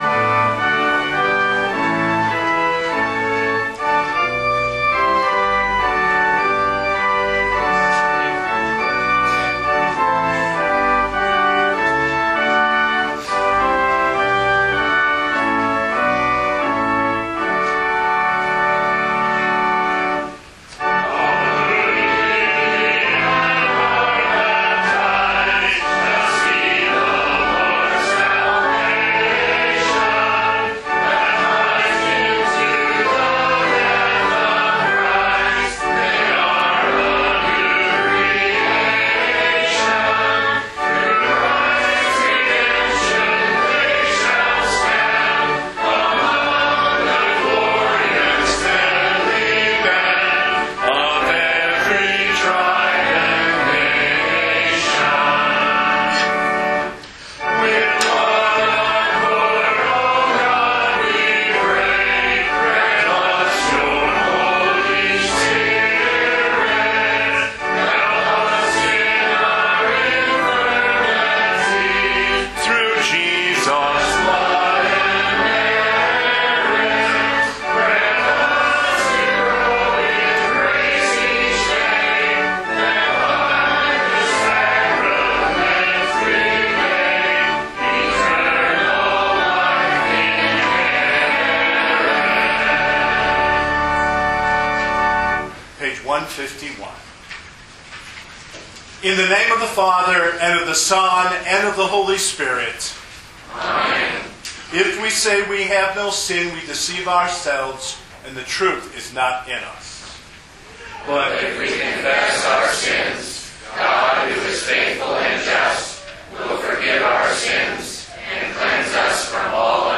This weeks Sermon Audio